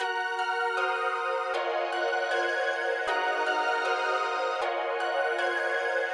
标签： 156 bpm Trap Loops Piano Loops 1.03 MB wav Key : C FL Studio
声道立体声